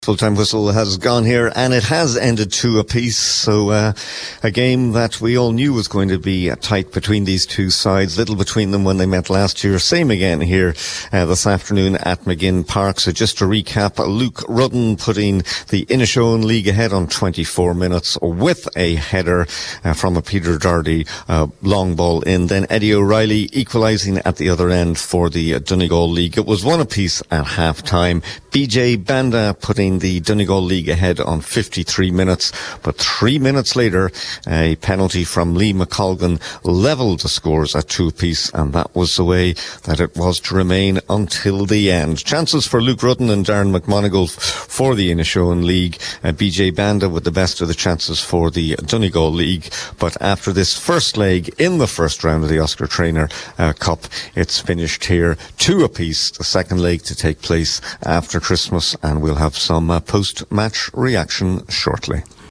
With the full time report from Buncrana